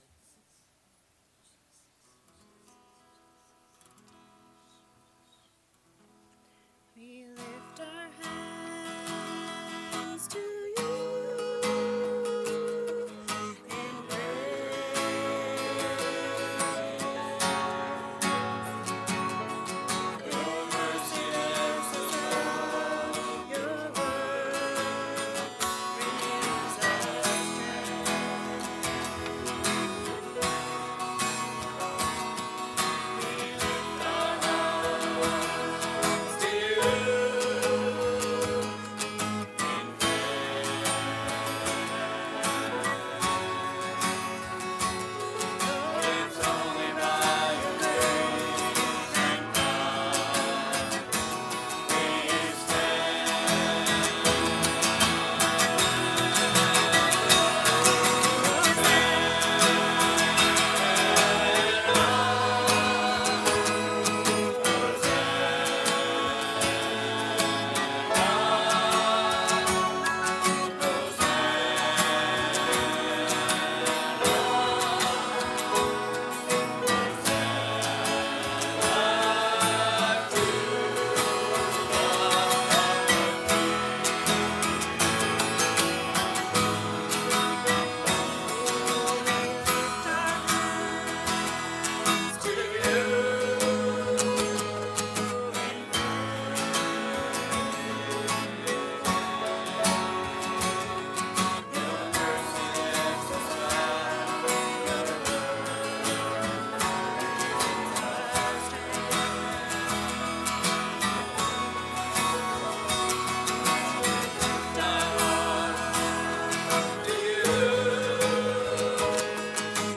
Unedited Praise 2 Convention 2020
Unedited-Praise-2020-SCC-Convention-2.mp3